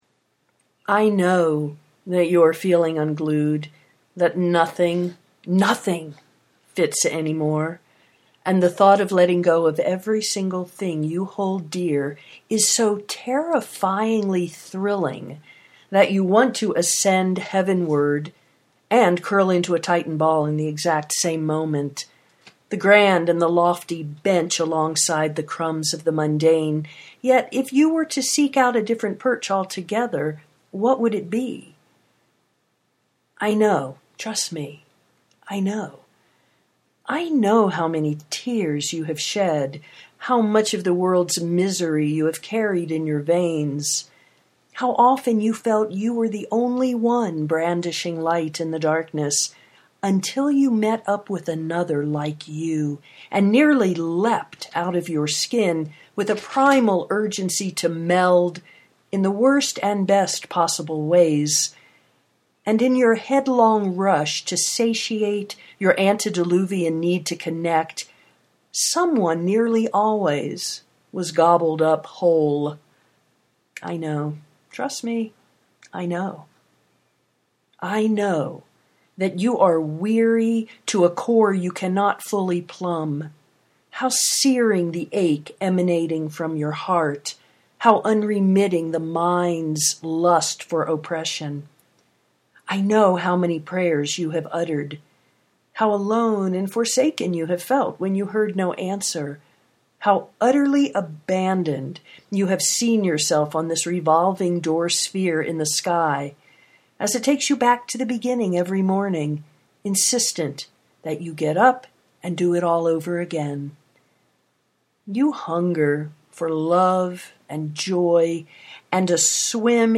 trust me, i know (audio poetry 3:40)